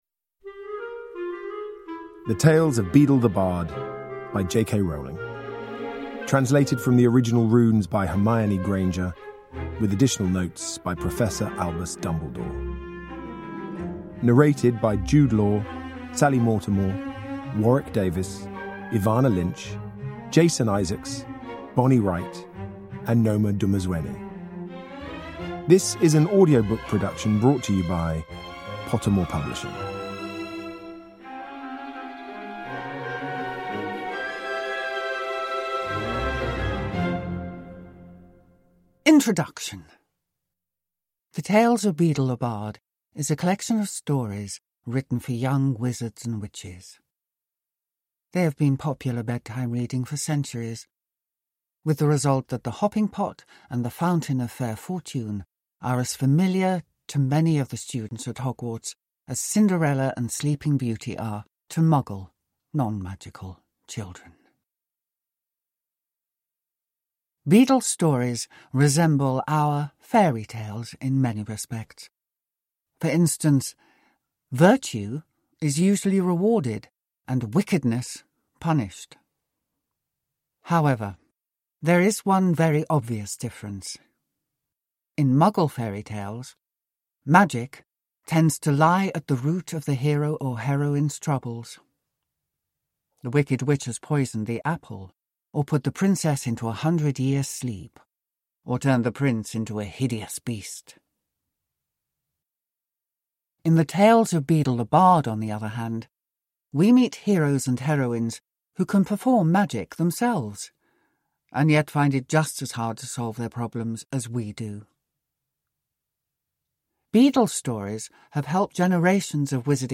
Tales of Beedle the Bard: A Harry Potter Hogwarts Library Book (ljudbok) av J.K. Rowling